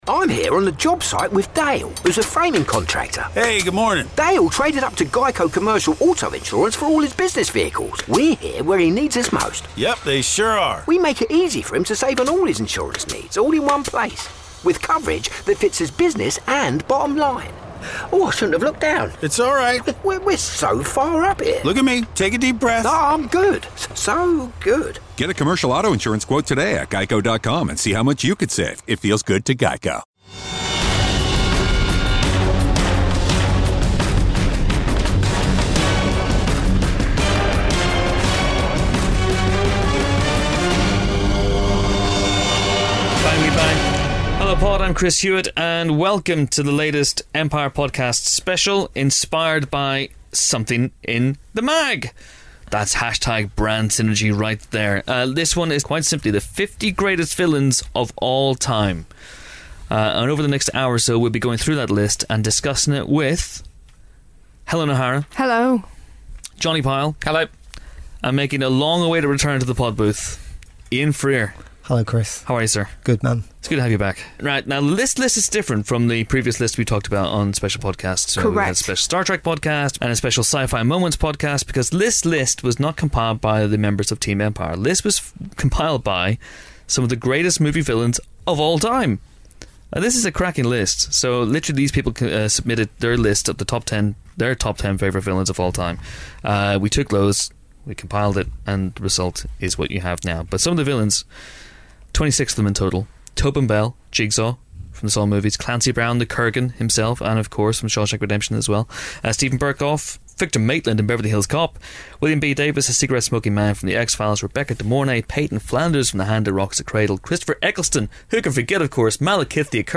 In honour of our 50 Greatest Movie Villains magazine feature, we discuss the ultimate bad guys and gals of cinema. (Listen to the end for evil cackling.)